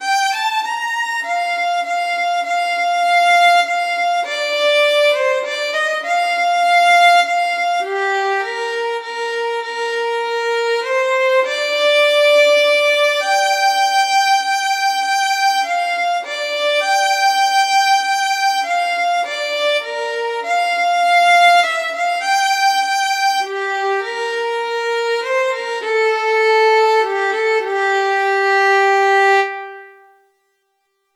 11_mockbeggar_30174_st2_ll.5_8_damaskrose_fiddle.mp3 (975.51 KB)
Audio fiddle of transcribed recording of stanza 2, lines 5–8, of first “Mock-Beggar Hall” ballad, sung to “Damask Rose”